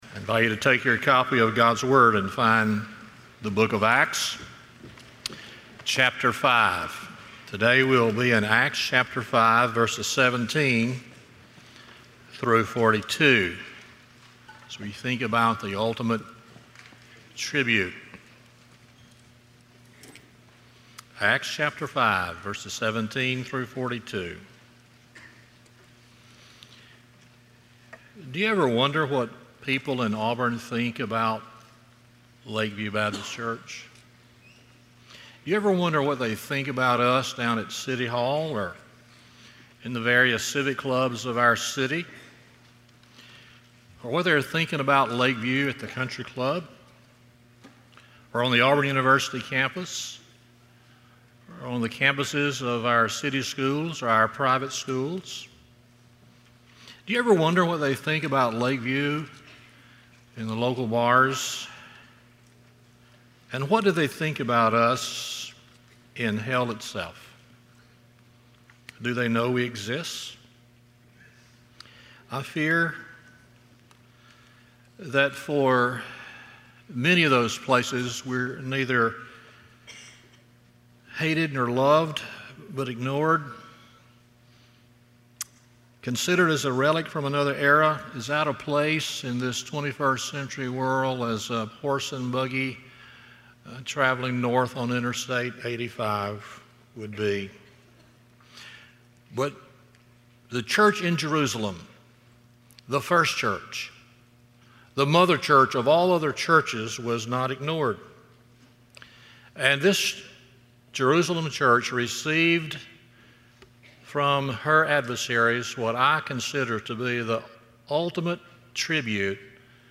Stand Alone Sermons
Service Type: Sunday Morning